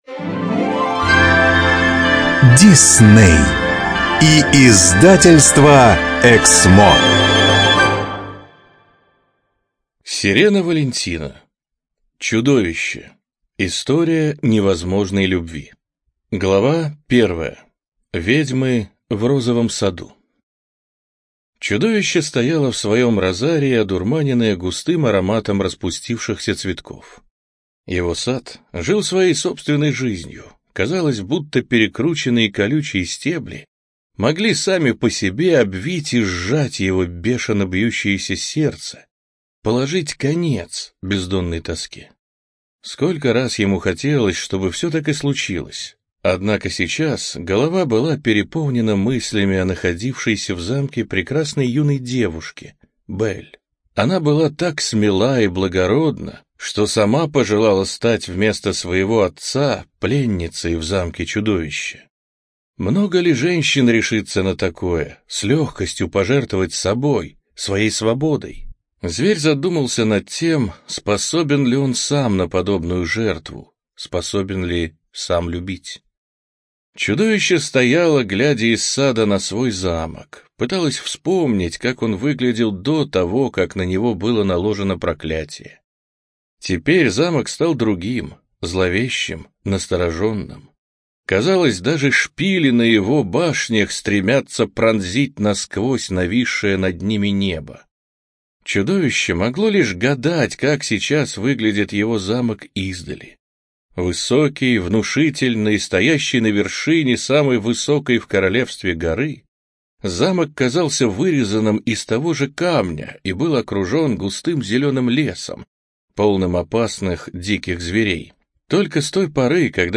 ЖанрСказки
Студия звукозаписиЭКСМО